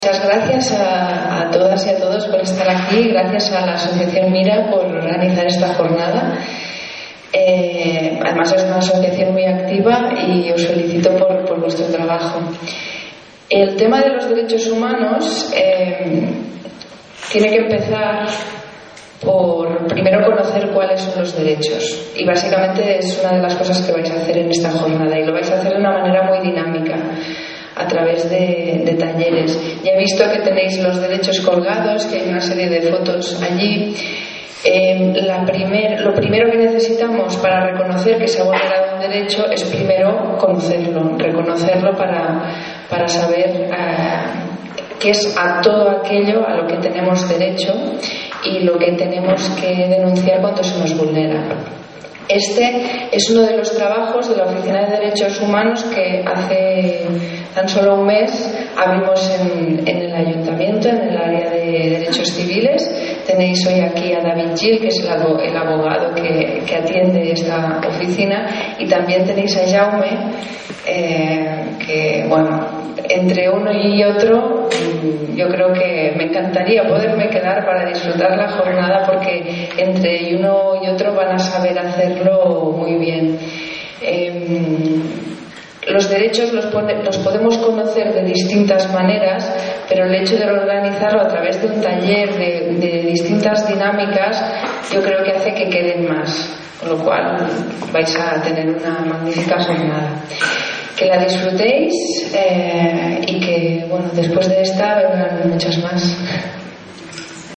Jornada sobre Drets Humans i Voluntariat al Centre Cívic de l’Ereta
La regidora Sandra Castro ha estat l’encarregada de donar la benvinguda als participants de la trobada organitzada per l’associació Amics MIRA
Tall de veu S. Castro.